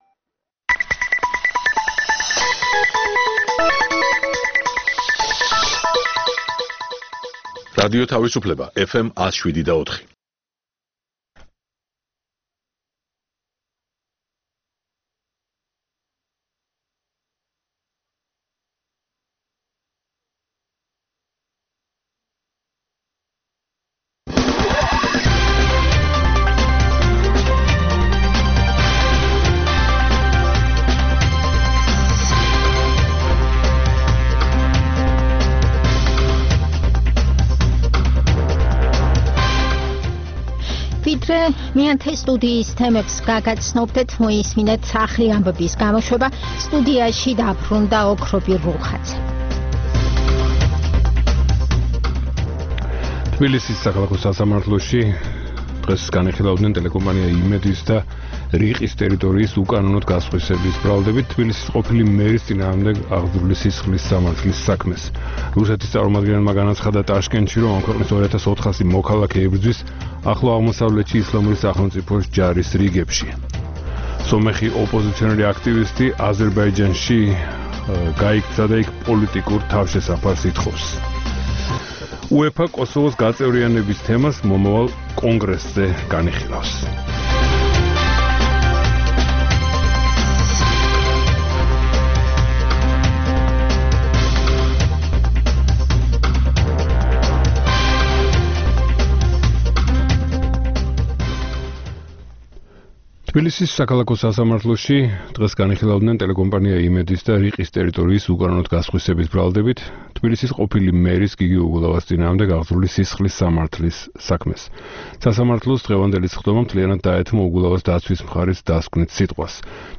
ეს პროგრამა ჩვენი ტრადიციული რადიოჟურნალია, რომელიც ორი ათეული წლის წინათ შეიქმნა ჯერ კიდევ მიუნხენში - რადიო თავისუფლების ყოფილ შტაბ-ბინაში, სადაც ქართული რედაქციის გადაცემების ჩასაწერად მე-10 სტუდია იყო გამოყოფილი. რადიოჟურნალი „მეათე სტუდია“ მრავალფეროვან თემებს ეძღვნება - სიუჟეტებს პოლიტიკასა და ეკონომიკაზე, გამოფენებსა და ფესტივალებზე, ინტერვიუებს ქართველ და უცხოელ ექსპერტებთან და ხელოვანებთან.